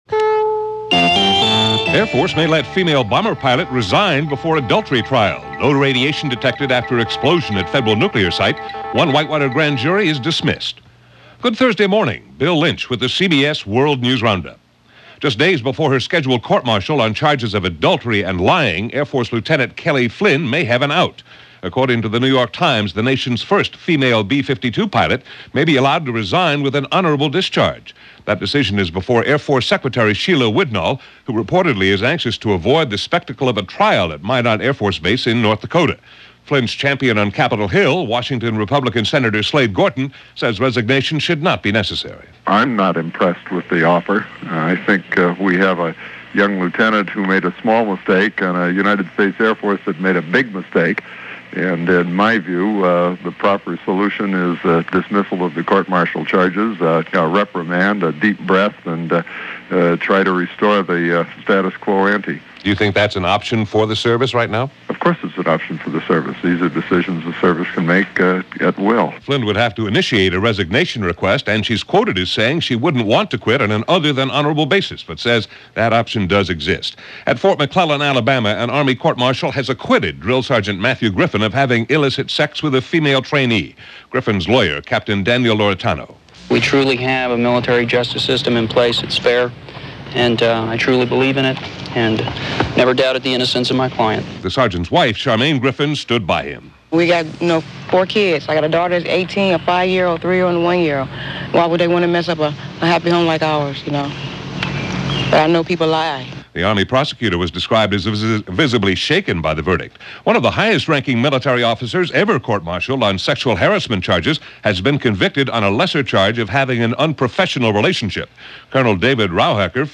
And that’s just a small slice of what happened this May 15th in 1997, as reported by The CBS World News Roundup.